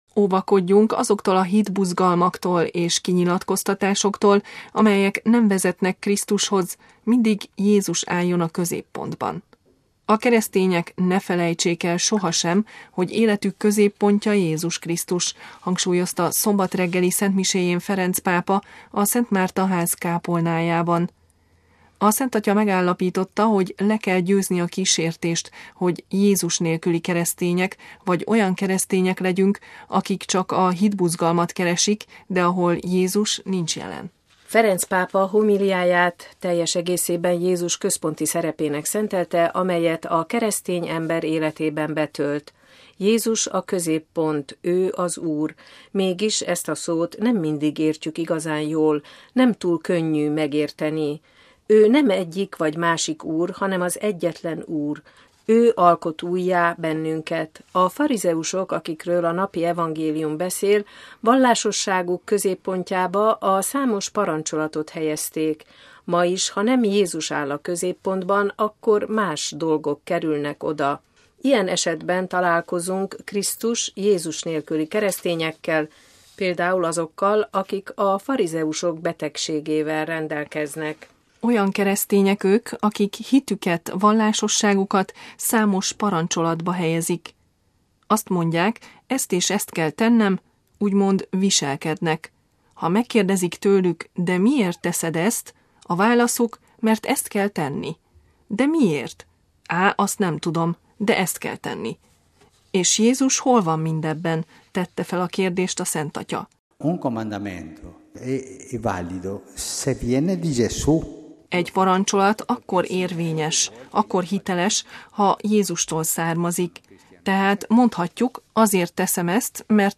MP3 A keresztények ne felejtsék el sohasem, hogy életük középpontja Jézus Krisztus – hangsúlyozta szombat reggeli szentmiséjén Ferenc pápa a Szent Márta Ház kápolnájában.
Ferenc pápa homíliáját teljes egészében Jézus központi szerepének szentelte, amelyet a keresztény ember életében betölt.